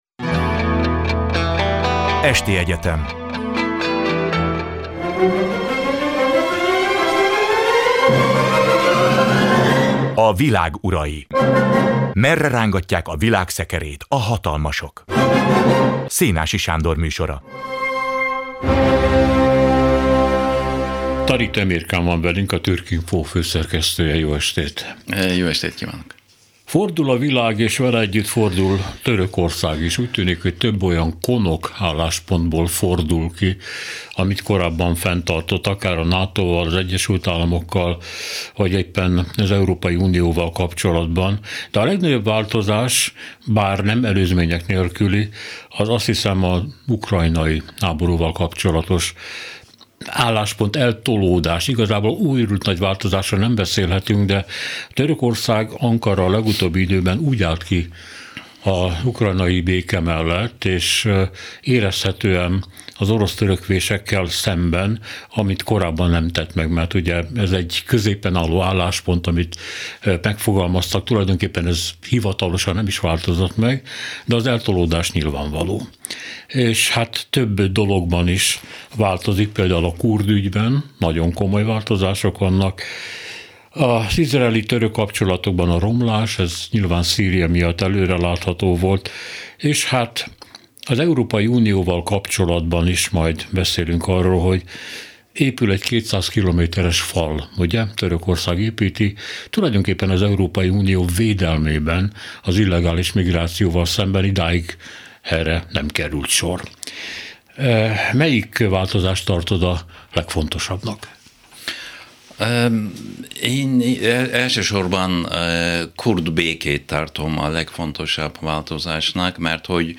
A Klubrádió ismeretterjesztő sorozata, történelmi precizitással és szakértői segítséggel vizsgálja a jelent. A majdnem egyórás beszélgetések a világ történéseit meghatározó és befolyásoló emberekről, hatalmakról, jelenségekről szólnak.